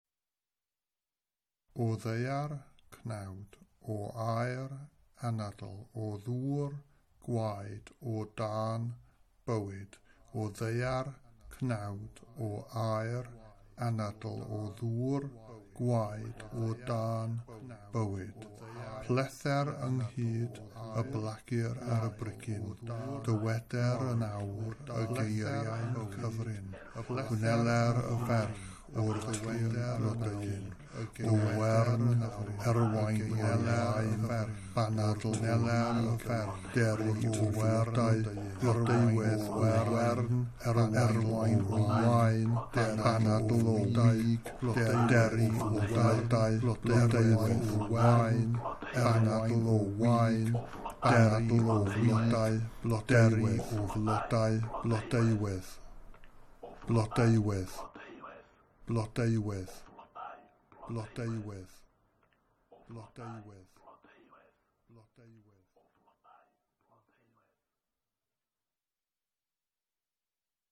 2.2 scene 2 incantation.MP3